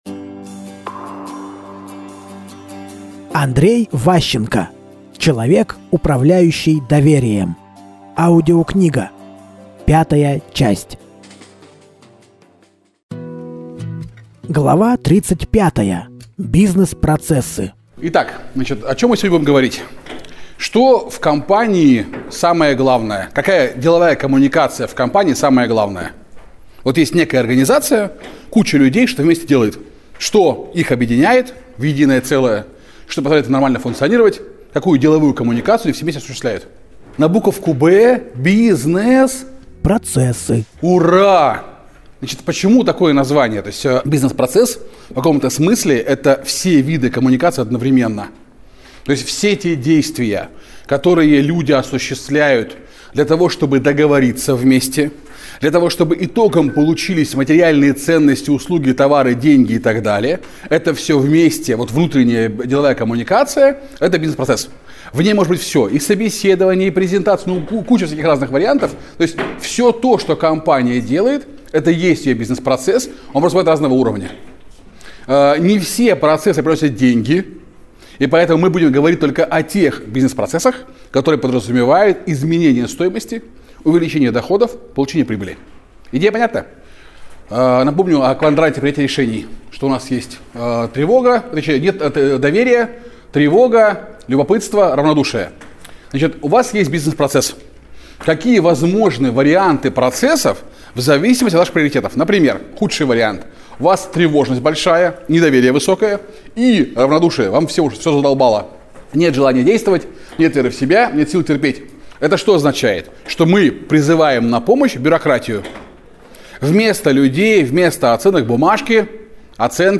Аудиокнига Человек, управляющий доверием. Часть 5 | Библиотека аудиокниг